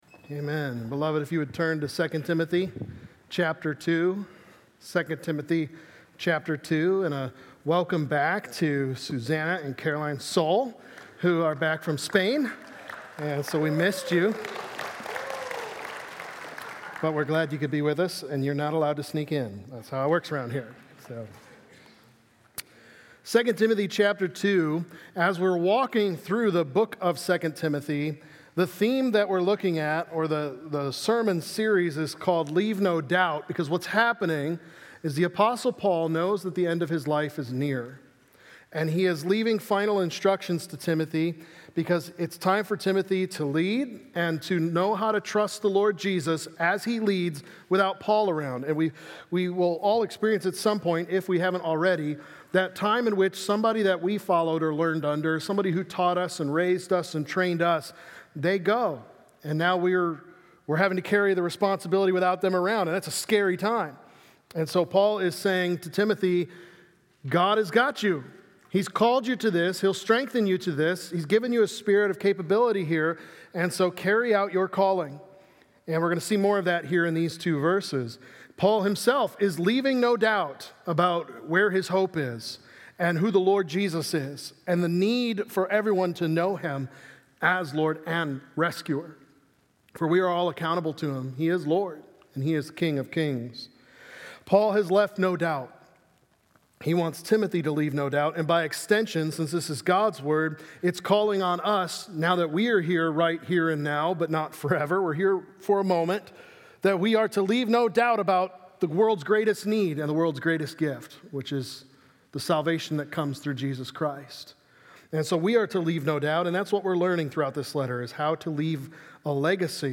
Leaving A Legacy of God’s Grace | Baptist Church in Jamestown, Ohio, dedicated to a spirit of unity, prayer, and spiritual growth